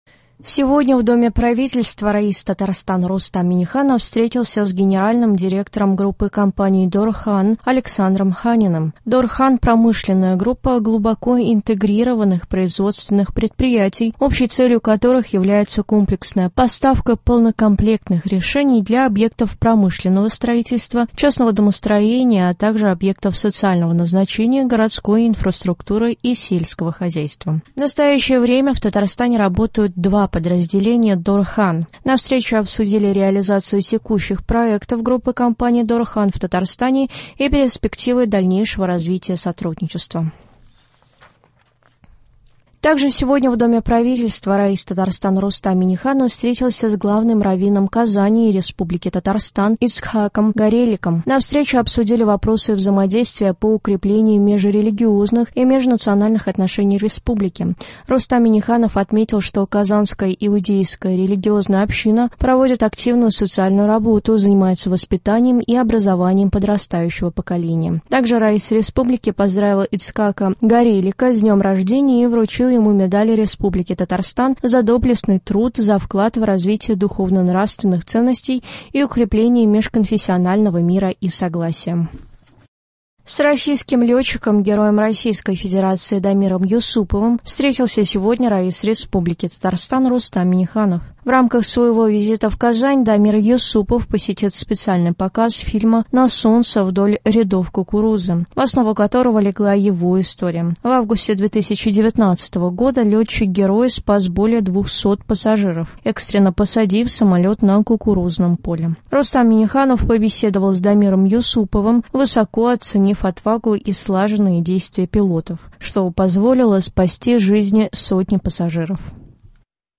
Новости (14.03.23)